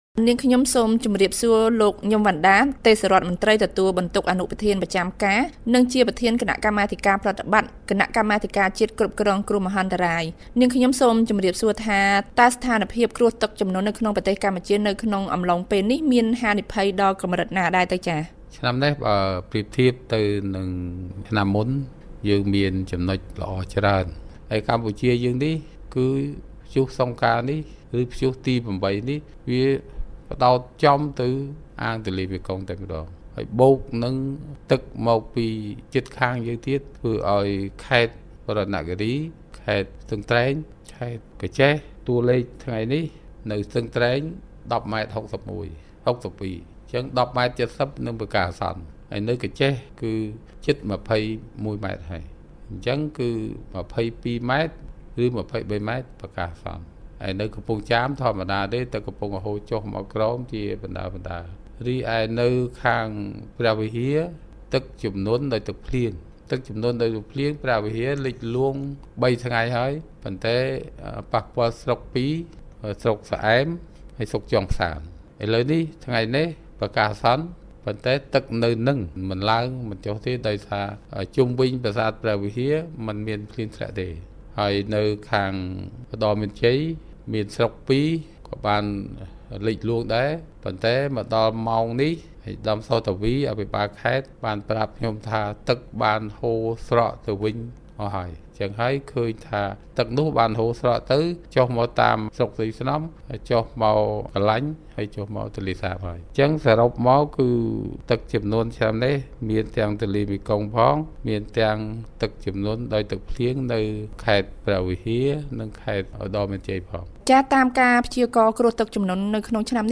បទសម្ភាសន៍ VOA៖ មន្ត្រីថា ស្ថានភាពទឹកជំនន់នៅកម្ពុជាមិនទាន់ដល់កម្រិតប្រកាសអាសន្នទេ